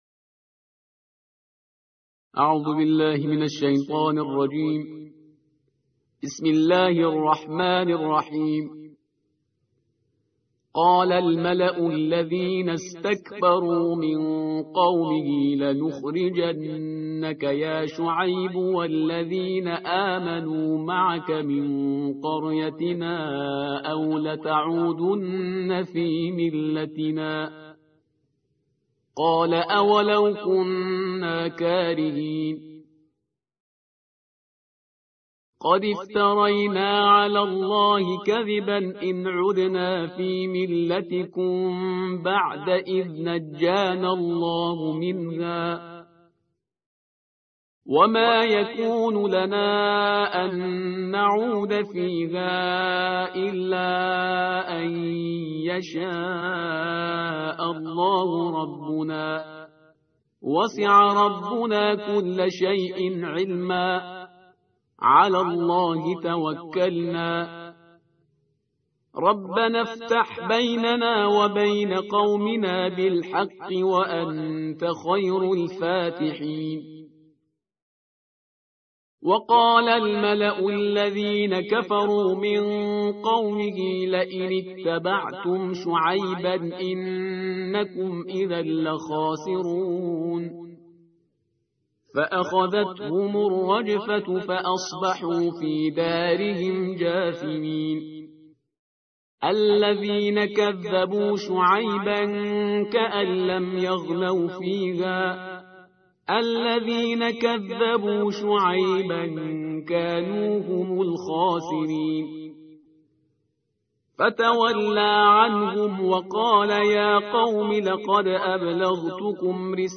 صوت | ترتیل‌ جزء نهم قرآن کریم
ترتیل جزء نهم قرآن‌ کریم در ماه مبارک رمضان، با صدای استاد خوش آوازه، شهریار پرهیزگار، تقدیم مخاطبان قرآنی ایکنا می‌شود.